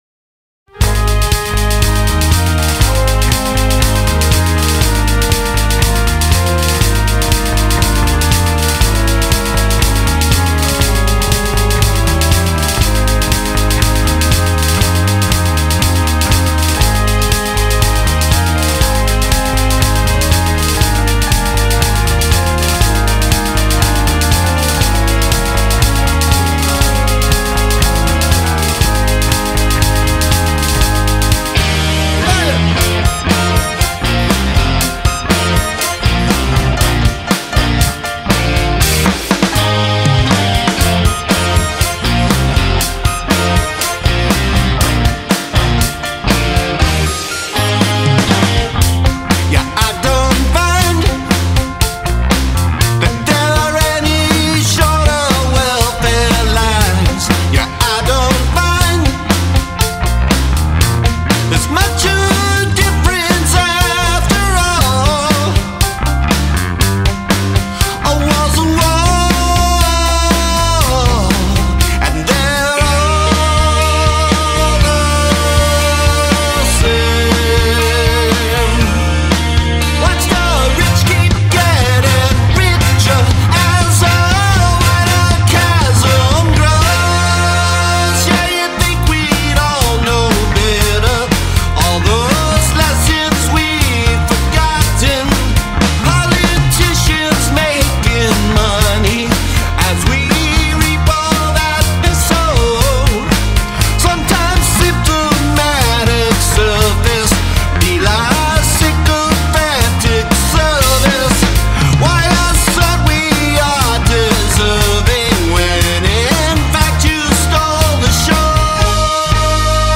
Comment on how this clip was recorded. This one was an exercise is removing clutter. The Bass and Vocals are digitally distorting BTW - I like the tune a lot - very clever - almost a 80s vibe.